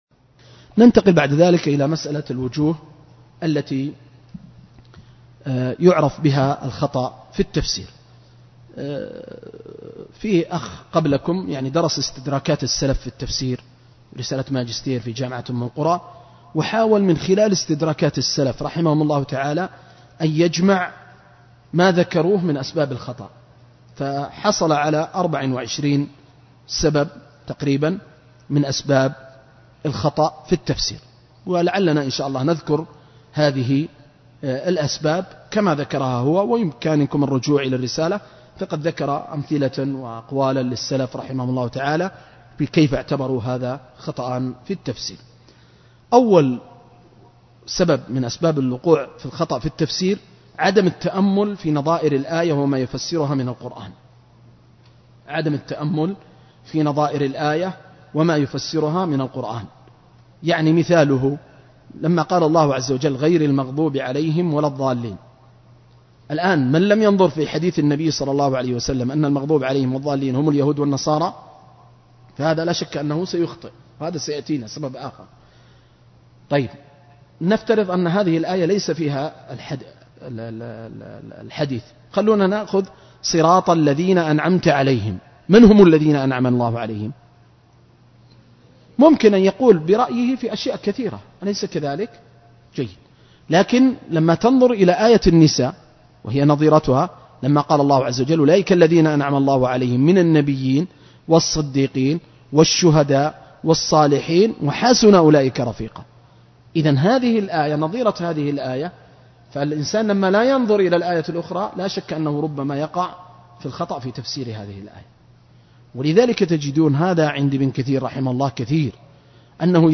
(دورة علمية في مسجد البلوي بالمدينة المنورة يوم الخميس 30/رجب/1435هـ)